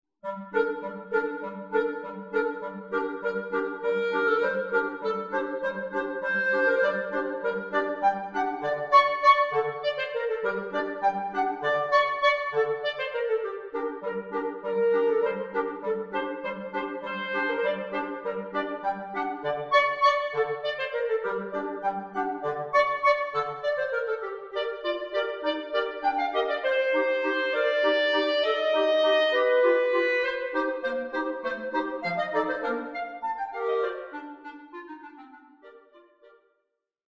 Three clarinets in Bb.